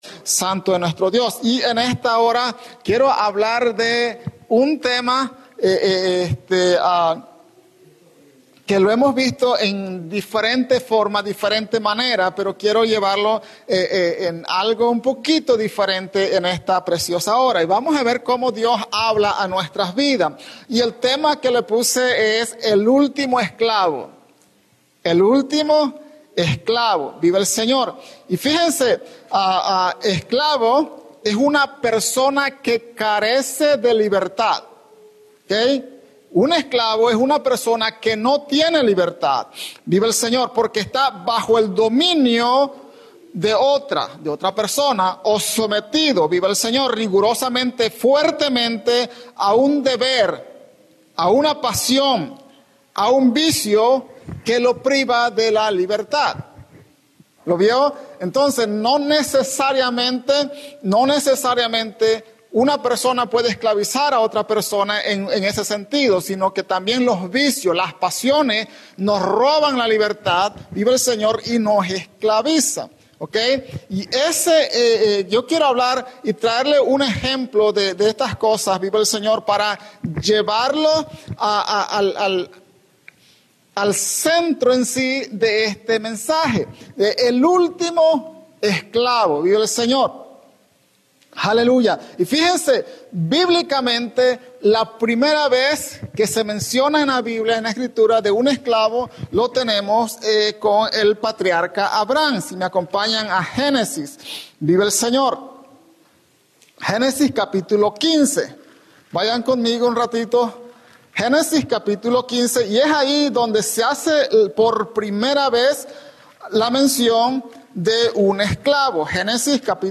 @ Norristown,PA